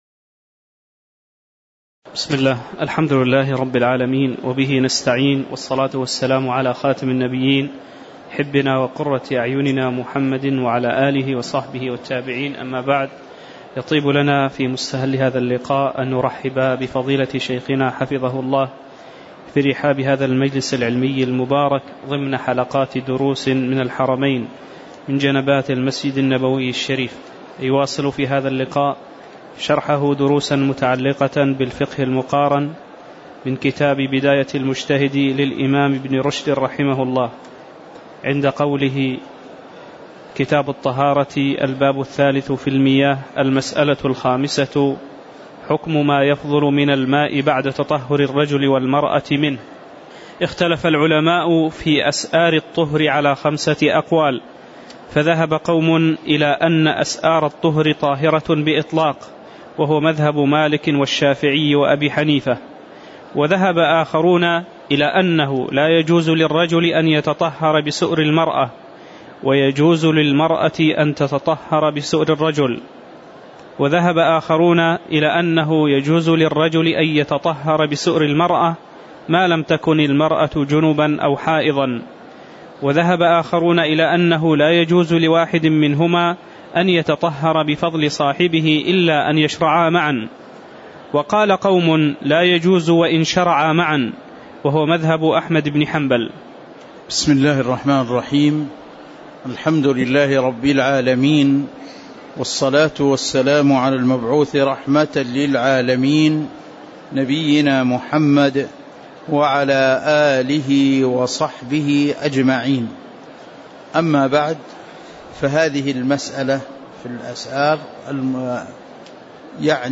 تاريخ النشر ١٢ صفر ١٤٤٠ هـ المكان: المسجد النبوي الشيخ